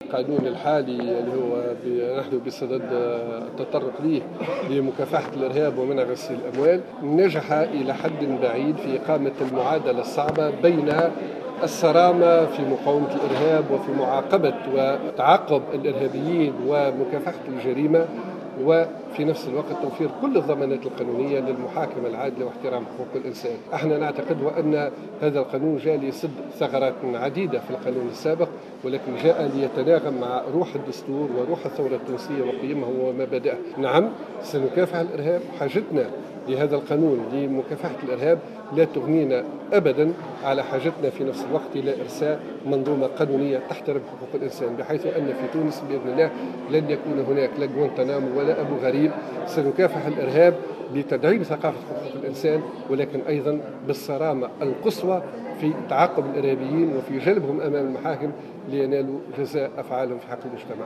أكد عصام الشابي اليوم على هامش الجلسة العامة التي انعقدت بالمجلس التأسيسي للنظر في مشروع القانون الأساسي المتعلق بمكافحة الإرهاب و منع غسل الأموال أن القانون الحالي نجح إلى حد بعيد في إقامة المعادلة الصعبة بين الصرامة في مكافحة الجريمة وتعقب الإرهابيين و توفير كل الضمانات القانونية للمحاكمة العادلة و احترام حقوق الإنسان.